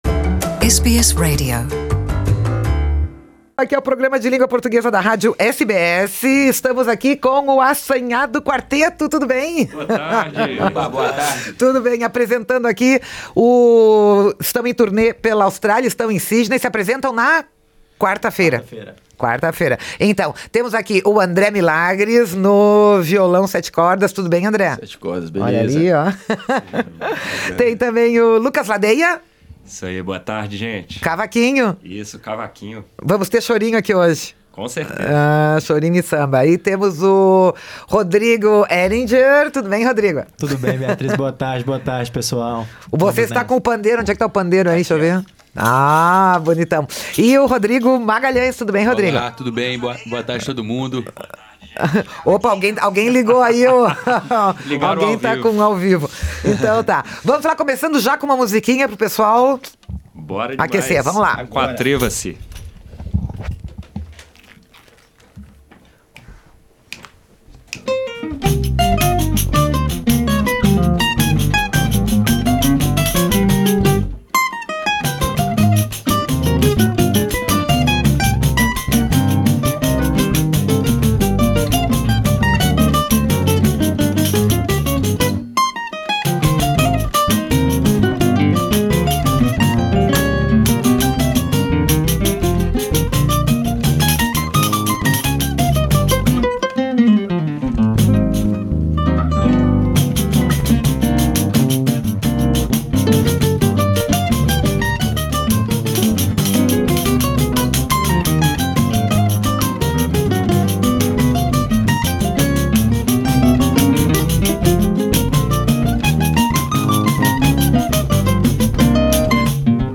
Eles participaram ao vivo do programa português da SBS e falaram sobre o tour pela Austrália, além de tocarem ritmos do tradicional chorinho ao samba.